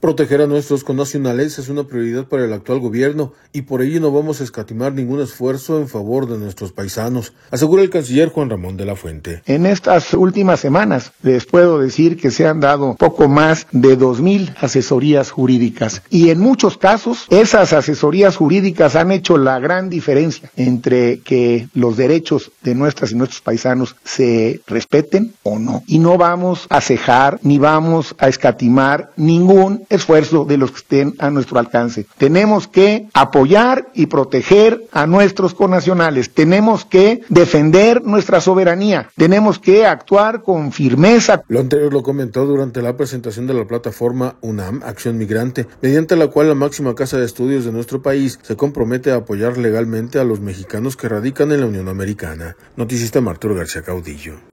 Lo anterior lo comentó durante la presentación de la Plataforma UNAM, Acción Migrante, mediante la cual la máxima casa de estudios de nuestro país se compromete a apoyar legalmente a los mexicanos que radican en la Unión Americana.